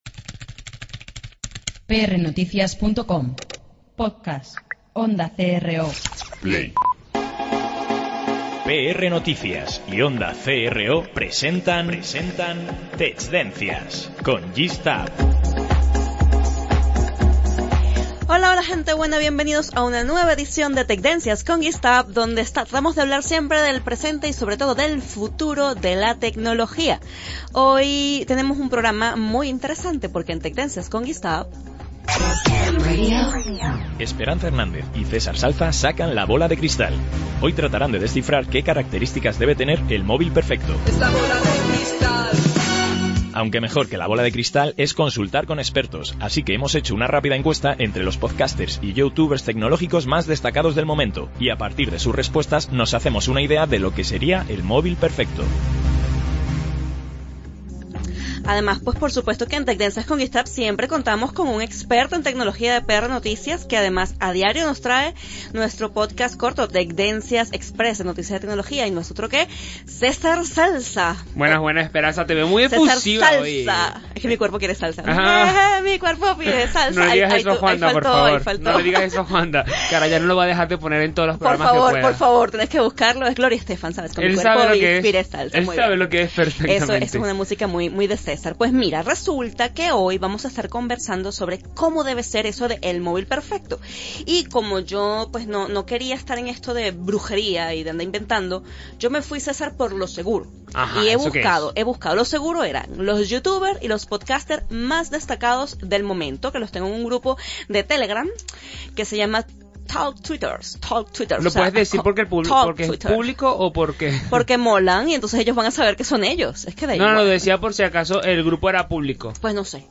Por eso hemos preparado un trabajo en el que escuchamos las opiniones de diversos fanáticos de la tecnología, youtubers y podcasters que piensan entre muchas otras cosas, que ese móvil del futuro debe contar con autonomía robusta, mejor pantalla y hasta un grifo para cerveza.